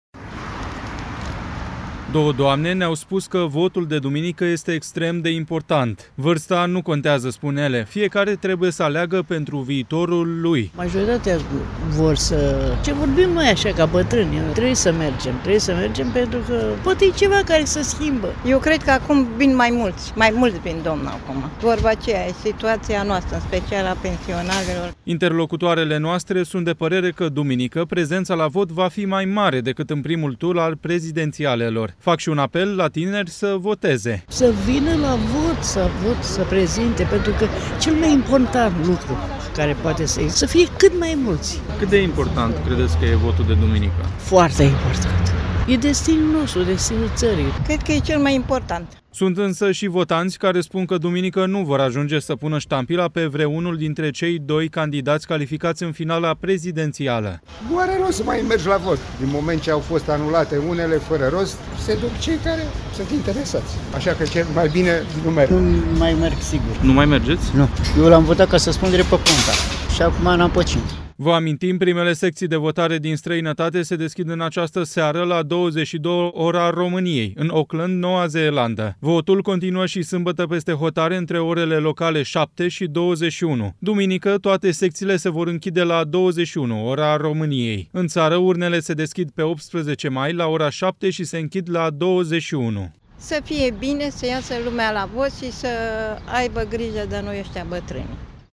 Două doamne ne-au spus că votul de duminică este extrem de important.
Unii bucureșteni spun că nu vor mai merge la vot